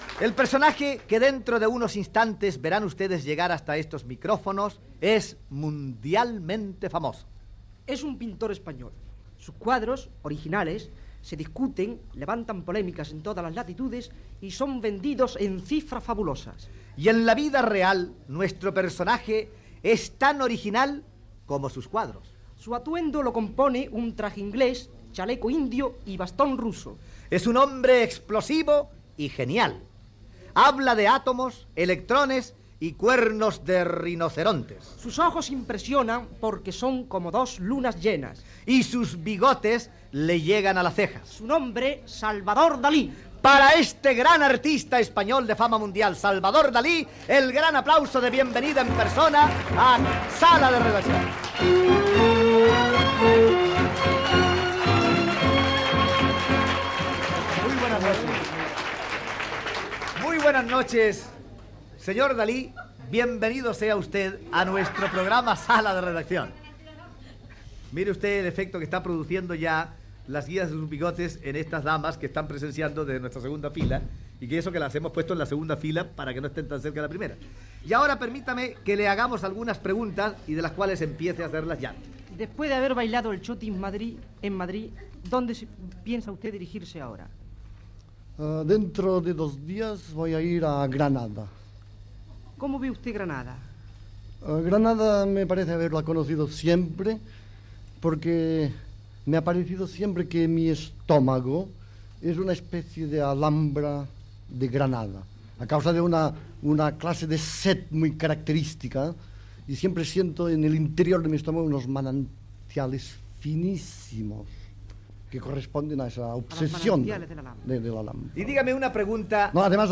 Presentació i entrevista al pintor Salvador Dalí que havia d'anar a Granada i acabava de pintar el quadre "La cena"
Entreteniment